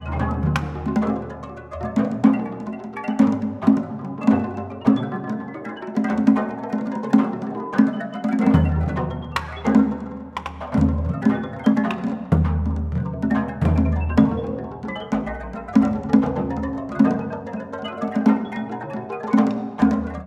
[from non-commercial, live recordings]